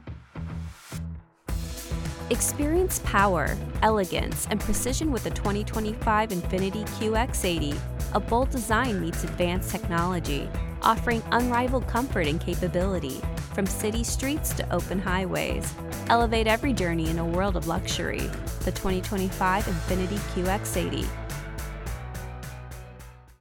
Voice-over artist with a warm, articulate, and soothing voice that brings calm and clarity to every project
Infiniti Car Ad